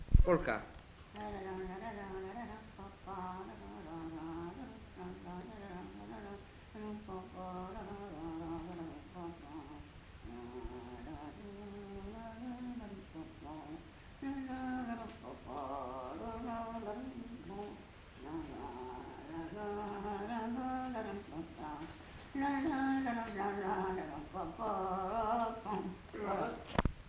Aire culturelle : Cabardès
Lieu : Mas-Cabardès
Genre : chant
Effectif : 1
Type de voix : voix de femme
Production du son : fredonné
Danse : polka